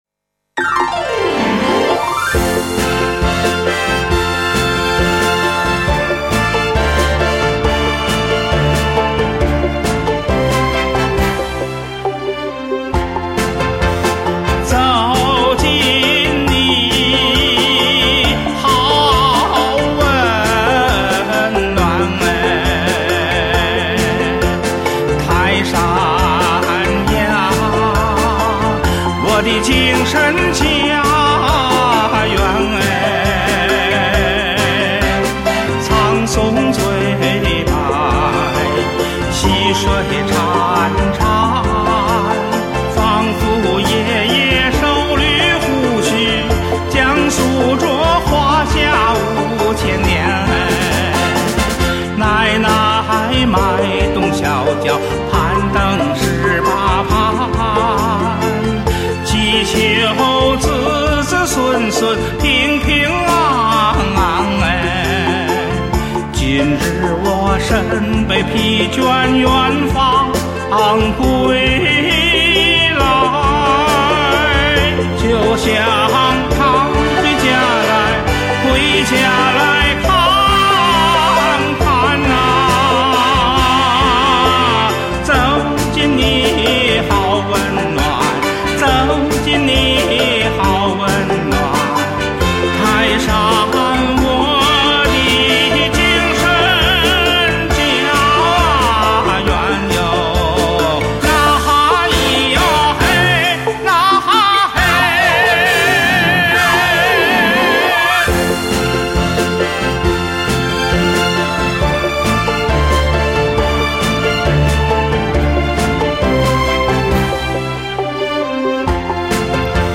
2008年录音